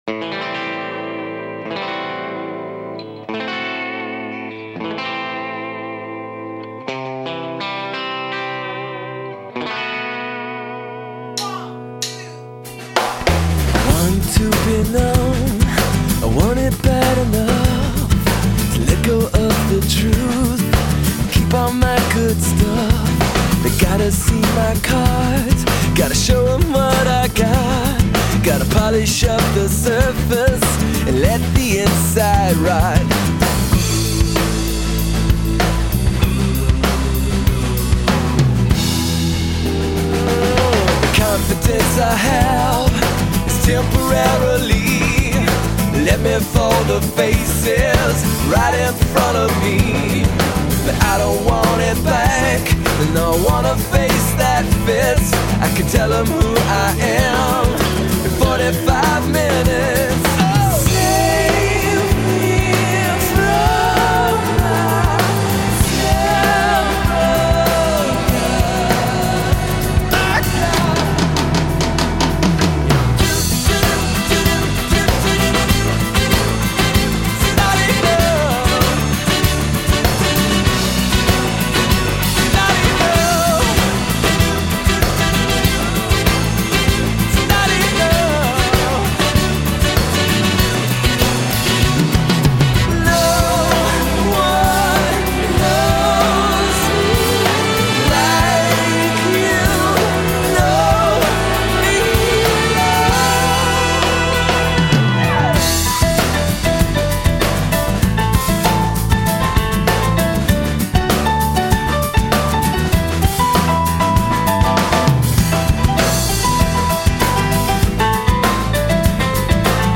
Singer-songwriter
and power pop.
includes a pseudo-salsa piano break and mariachi horns.